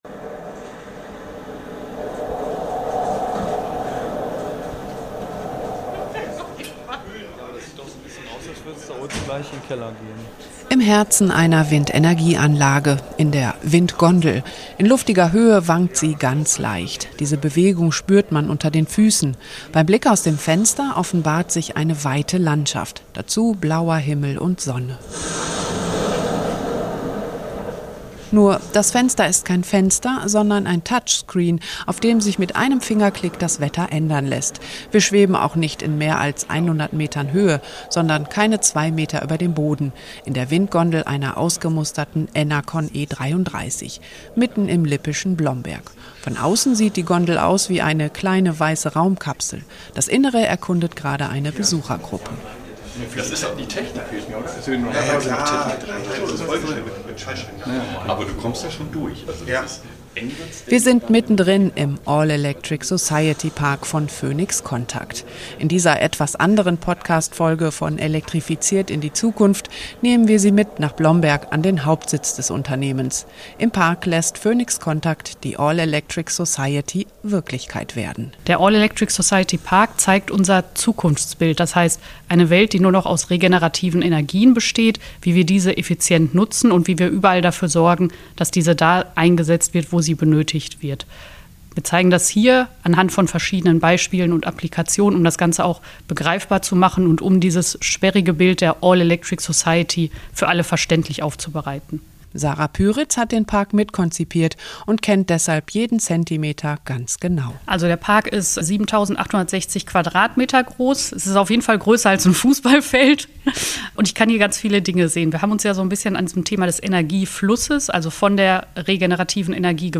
Wir schweben in einer Windgondel über Blomberg. Laufen über Pflastersteine, die Sonnenenergie einfangen, und lassen unsere Blicke über Windtree und Solar-Trackern schweifen. An diesen und vielen weiteren Stationen im Park wird das Zukunftsbild einer nachhaltigen Welt sichtbar und erlebbar: von der regenerativen Energieerzeugung über den Energietransport bis zum Verbrauch.